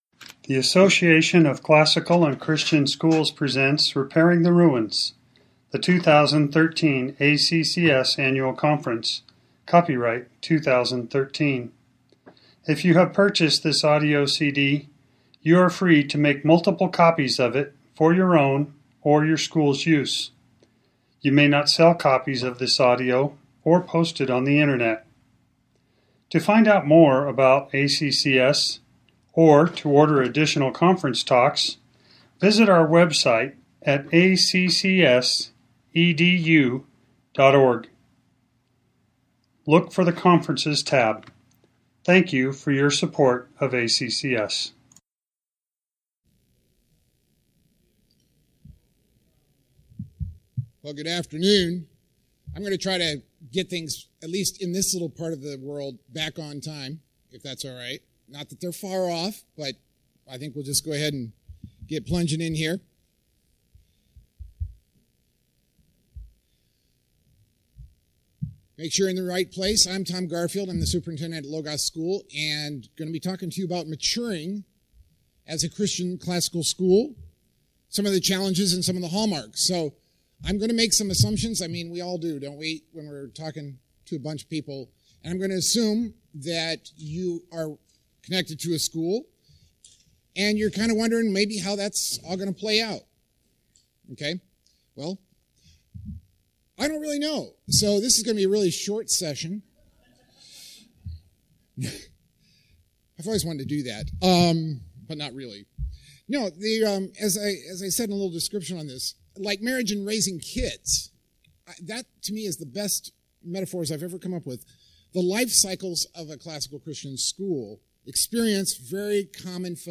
2013 Foundations Talk | 1:02:51 | All Grade Levels, Leadership & Strategic
Additional Materials The Association of Classical & Christian Schools presents Repairing the Ruins, the ACCS annual conference, copyright ACCS.